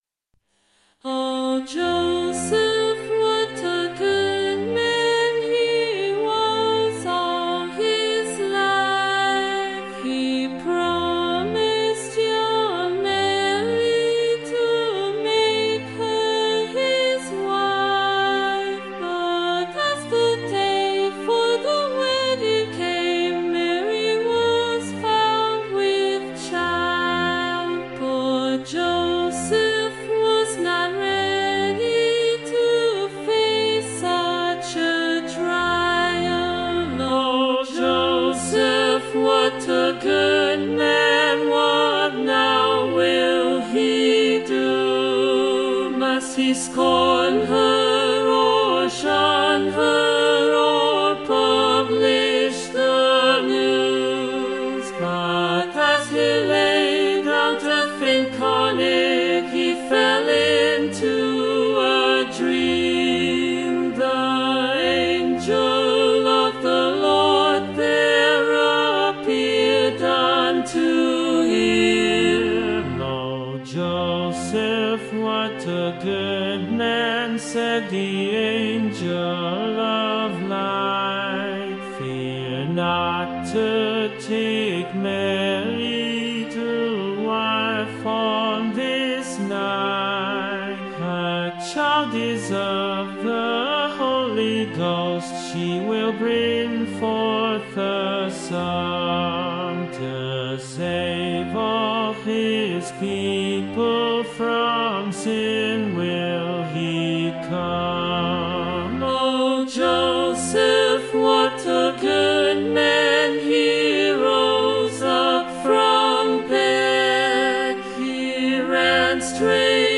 Vocal Solo Medium Voice/Low Voice Christmas